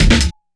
kick-snare01.wav